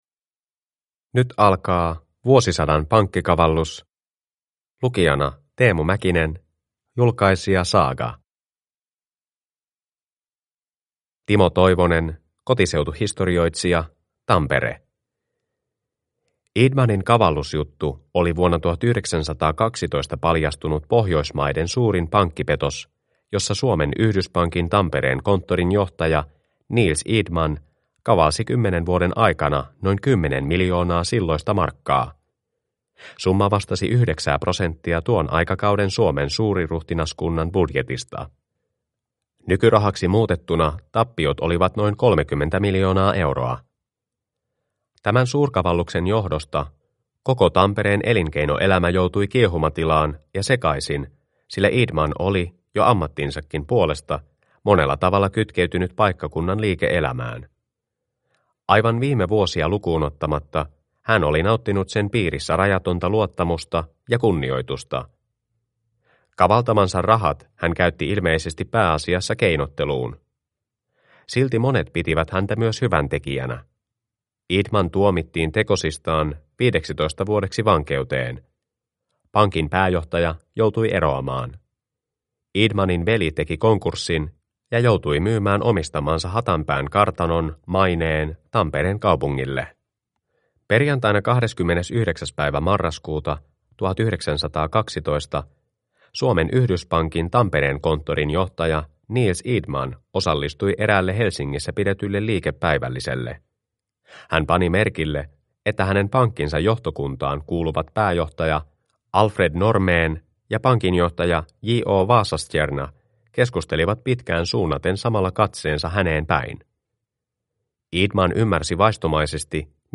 Vuosisadan pankkikavallus (ljudbok) av Eri tekijöitä